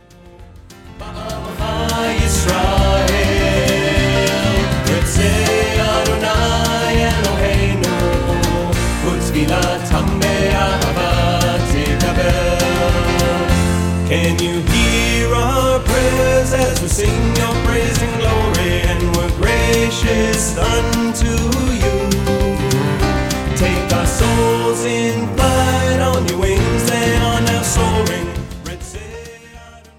A collection of introspective yet joyous songs